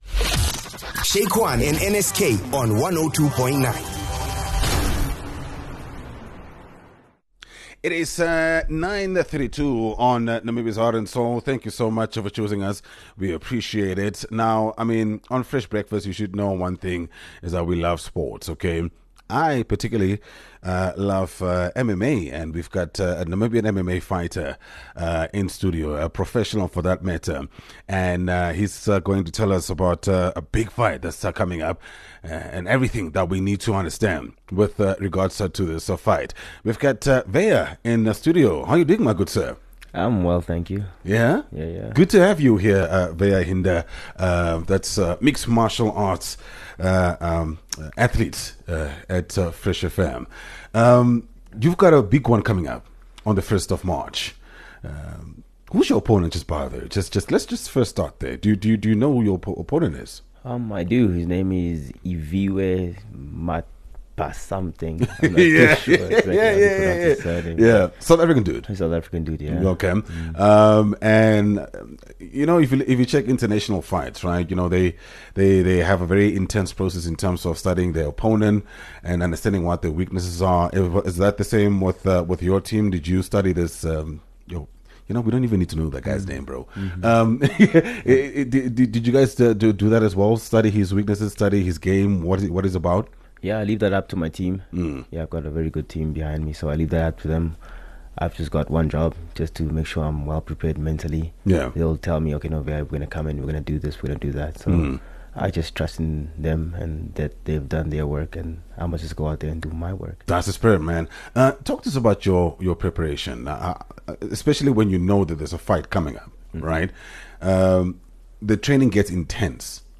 a chat about his upcoming challenge